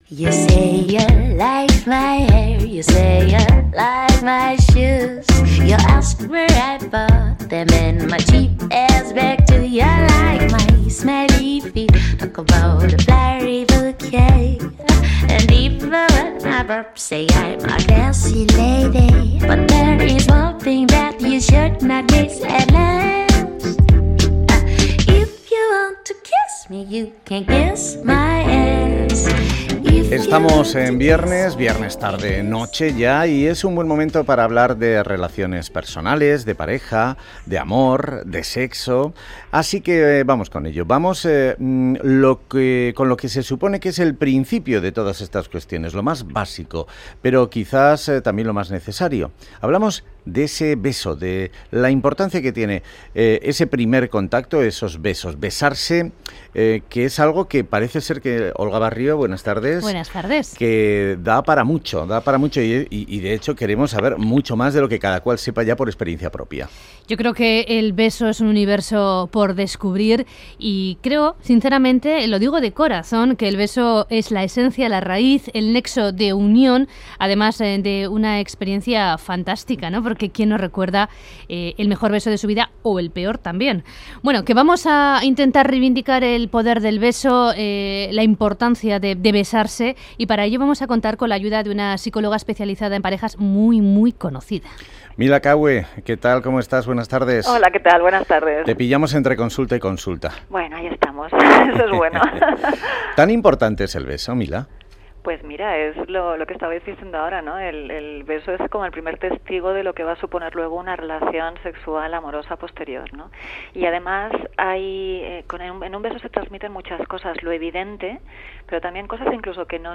Os dejo hoy une entrevista deliciosa que hicimos el pasado día 13 de marzo (2015) para el Programa Graffiti, de Radio Euskadi, sobre la importancia de besar y de los besos.